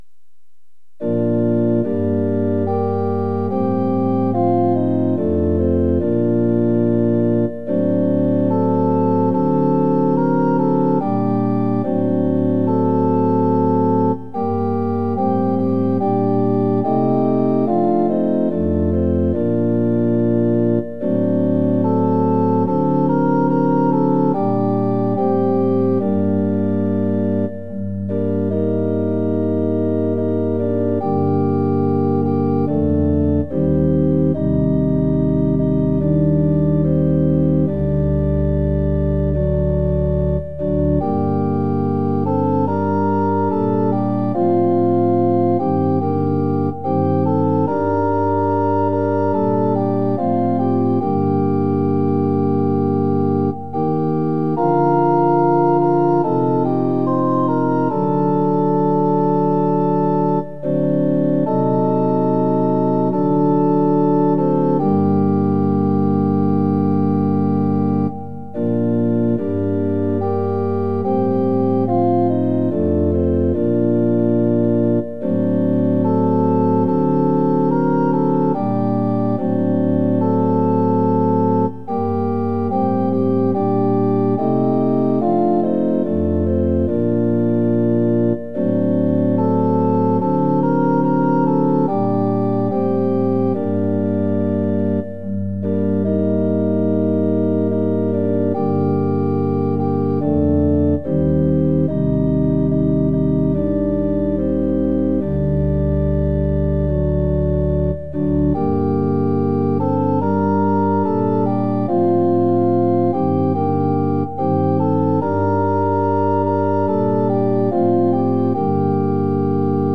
◆　４分の４拍子：　一拍目から始まります。